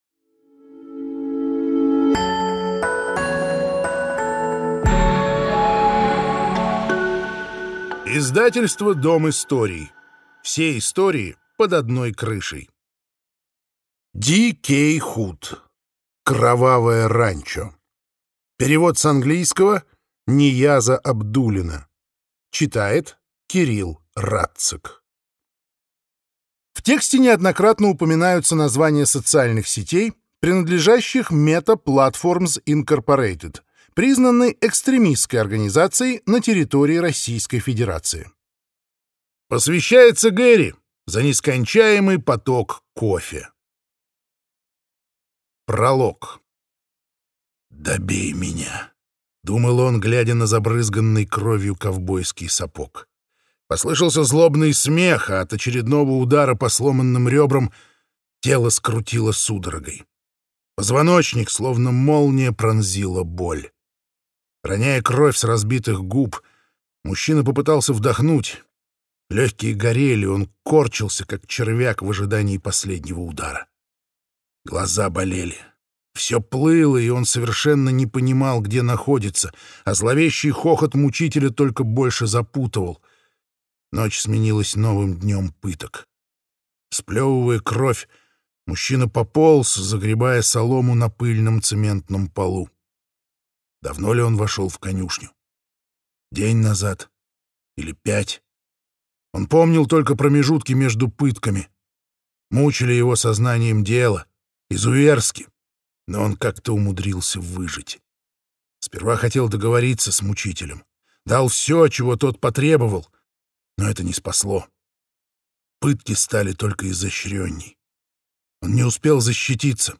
Аудиокнига Кровавое ранчо | Библиотека аудиокниг